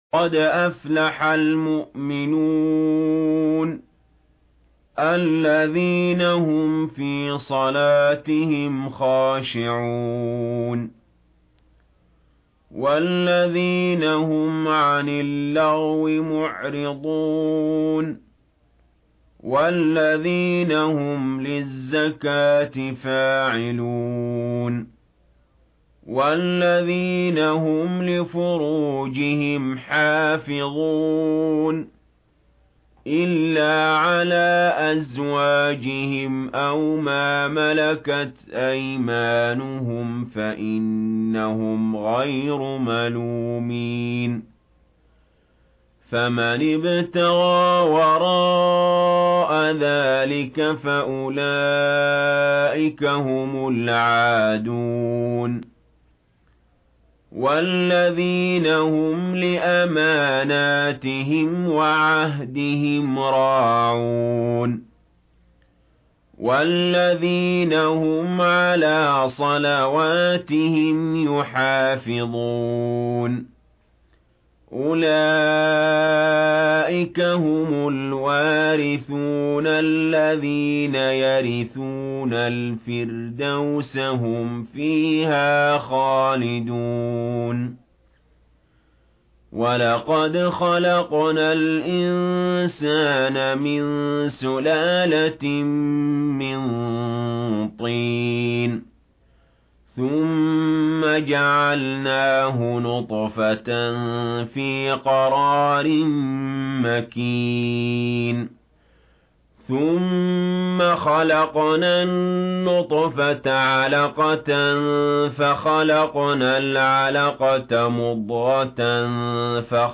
قرآن - قاری قارئ علي جابر